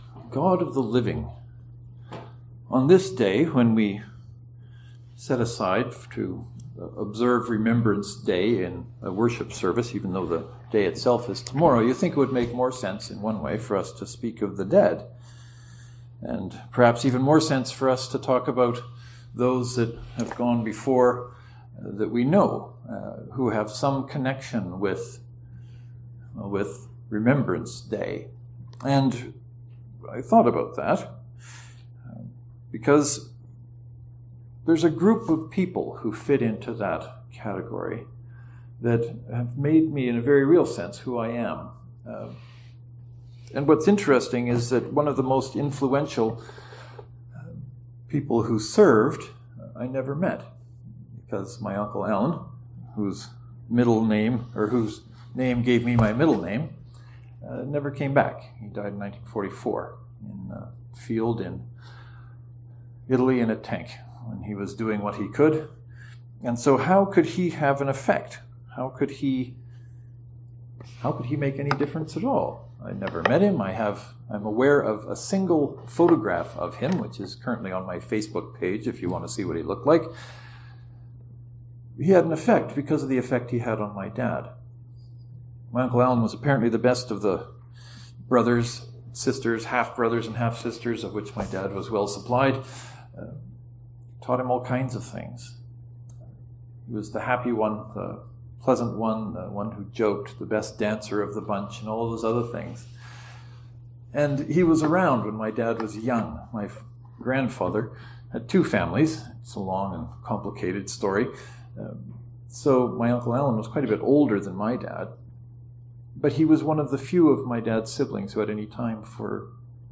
And then I preached about God being the God of the living. As some of you know, I don’t preach from a manuscript or notes very often.